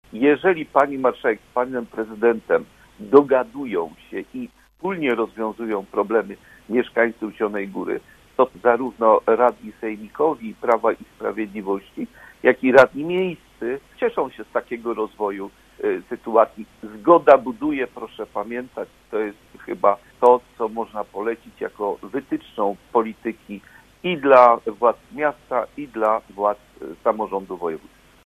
Jako radni miejscy i wojewódzcy wskazywaliśmy, że są instytucje, których organem założycielskim jest samorząd województwa, a które powinny być wspomagane przez zielonogórski magistrat, jak szpital czy lotnisko w Babimoście – mówił w Rozmowie Punkt 9, radny sejmiku z Prawa i Sprawiedliwości: